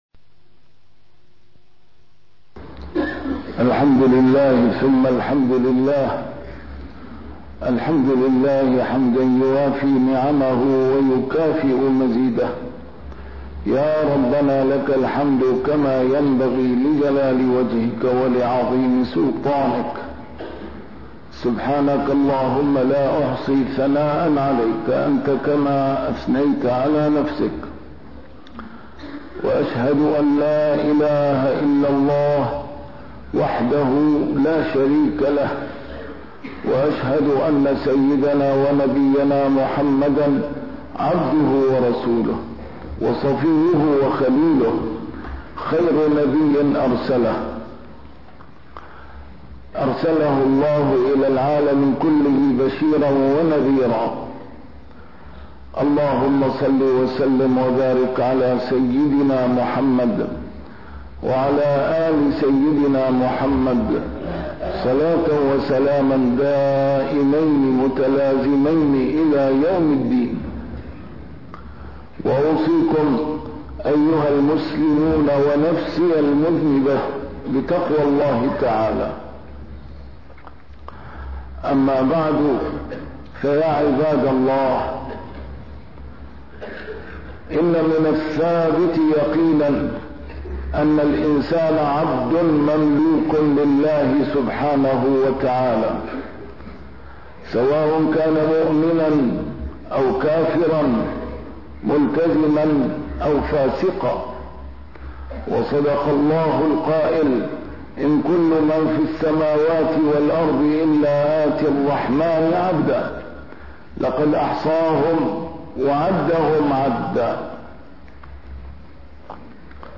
A MARTYR SCHOLAR: IMAM MUHAMMAD SAEED RAMADAN AL-BOUTI - الخطب - العبودية